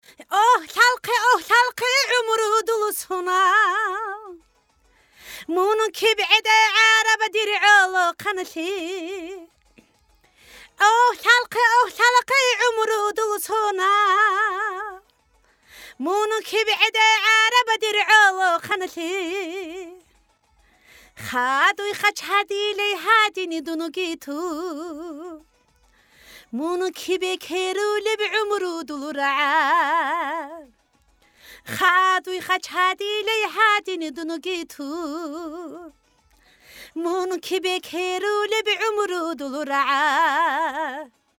Каким местом лимитер мешает качеству звука в ст/чен,только подрезает отдельные пики и проверте динамику в вашем файле и в моём.